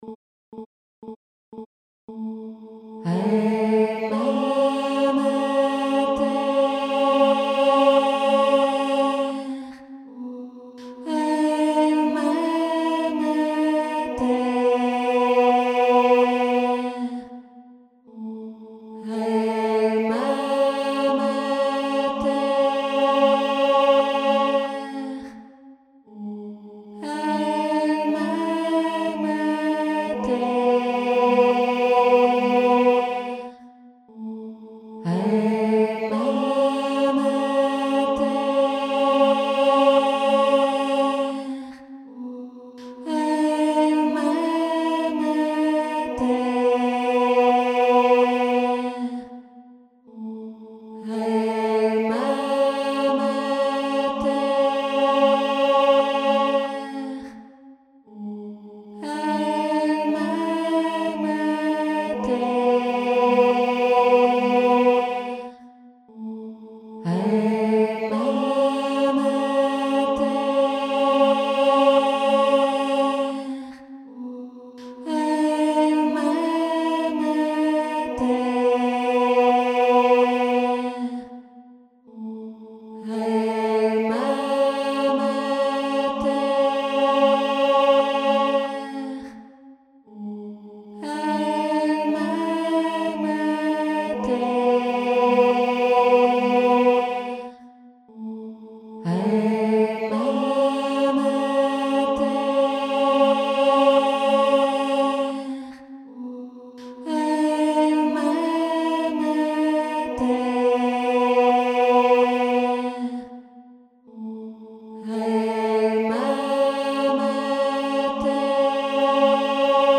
4 voix : Chœur + 3 solistes (alto - mezzo - soprano)
polyphonies mariales